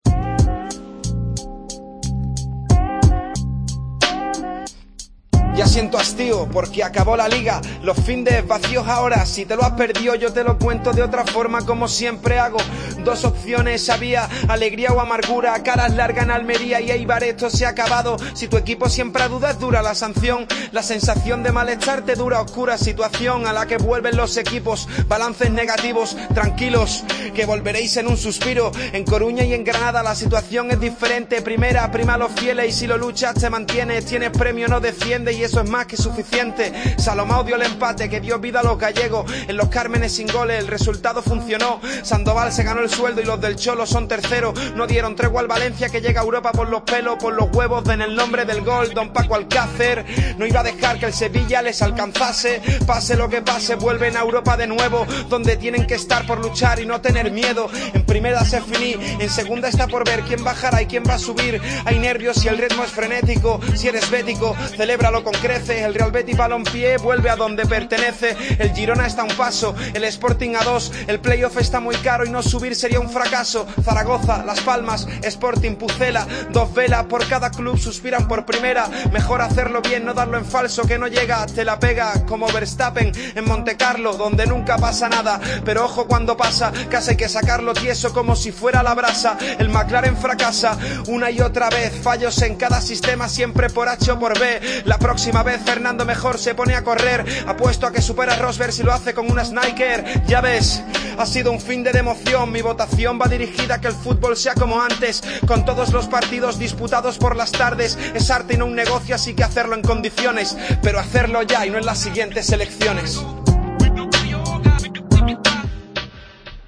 Tiempo de Juego a ritmo de rap